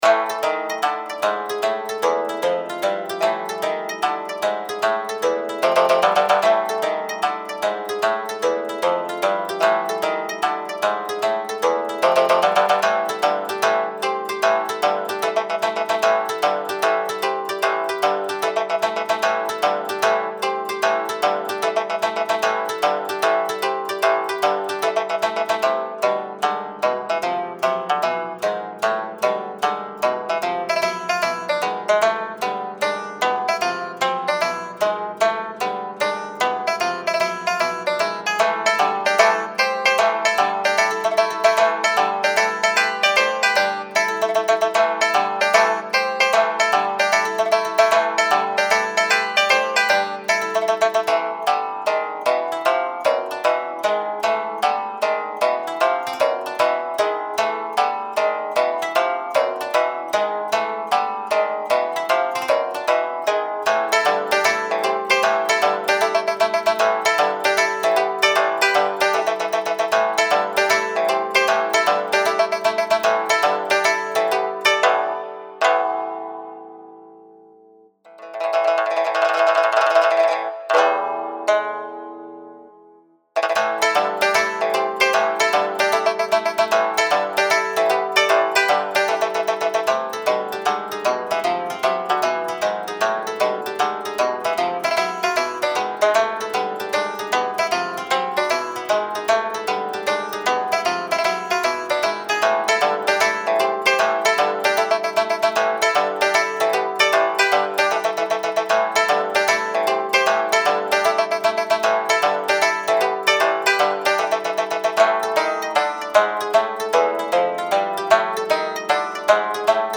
五絃琵琶だ！！！